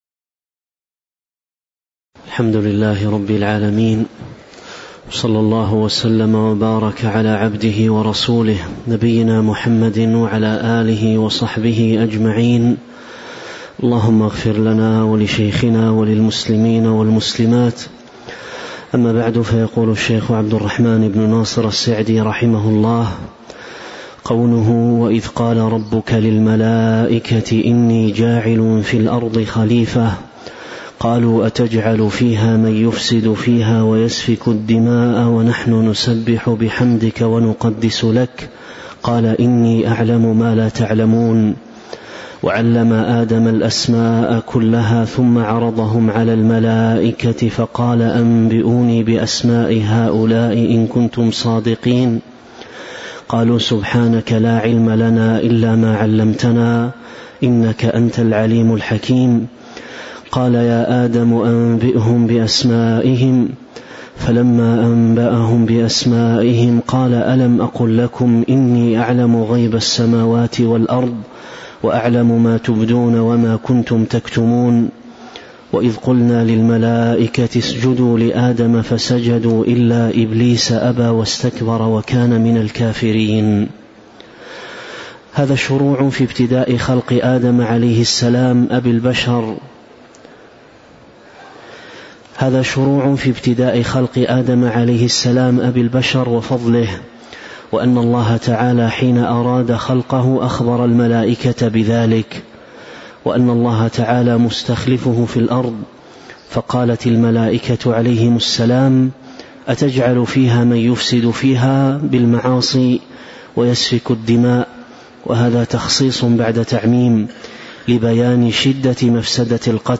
تاريخ النشر ٦ ربيع الثاني ١٤٤٦ هـ المكان: المسجد النبوي الشيخ